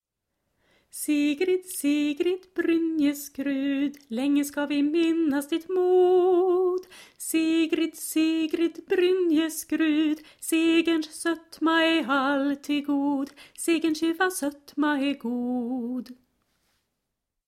Inspelning, refräng (understämma):